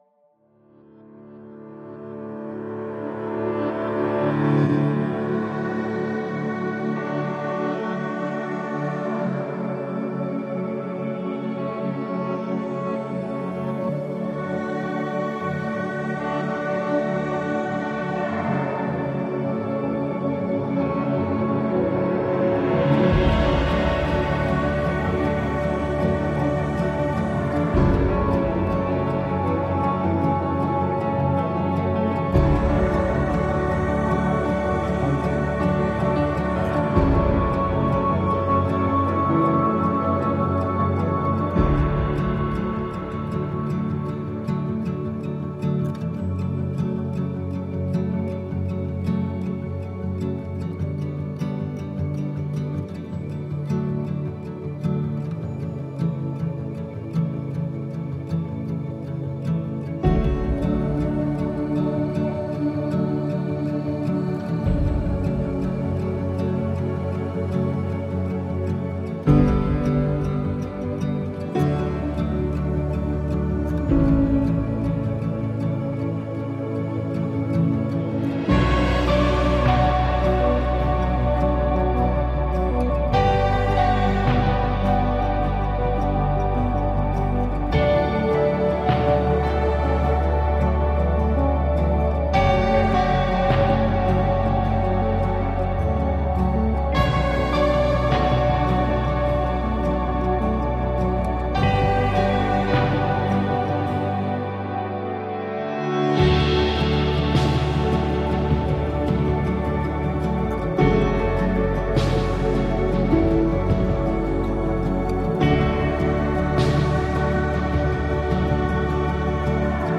موسیقی اینسترومنتال